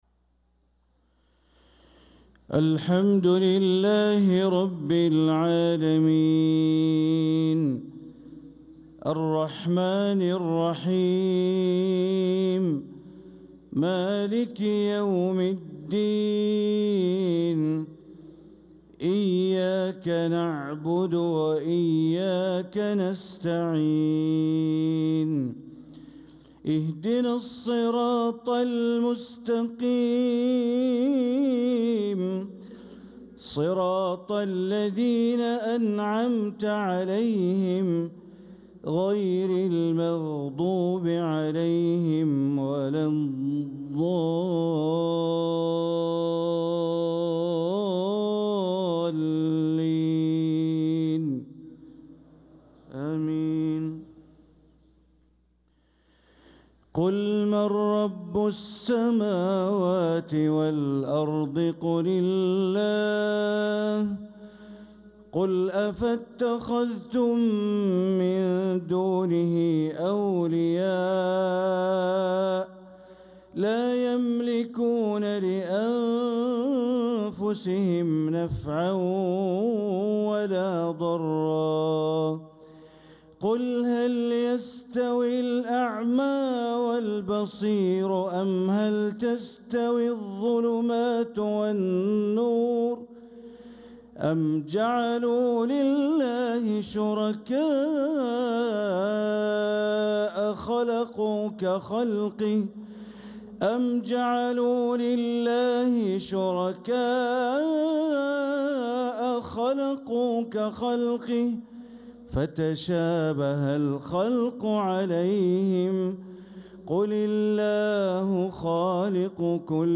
صلاة الفجر للقارئ بندر بليلة 16 رجب 1446 هـ